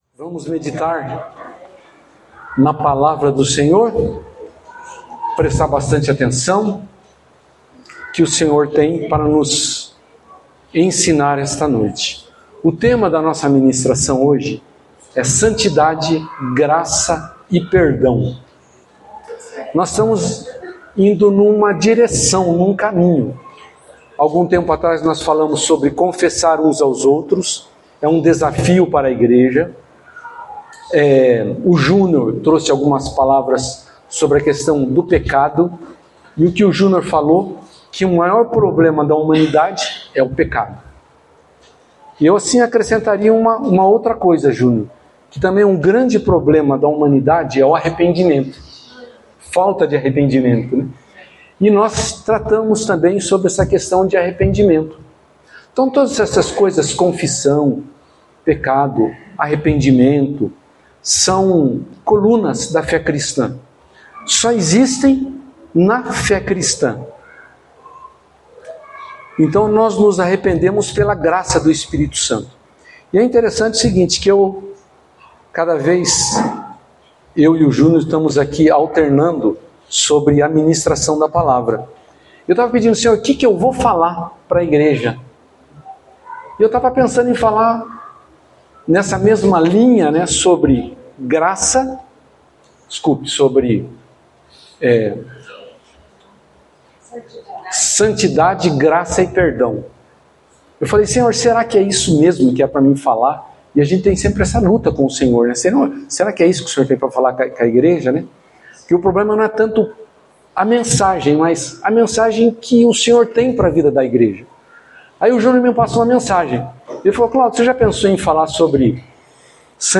Palavras ministradas